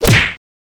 benachrichtigungston-punch.mp3